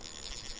Spool_01.ogg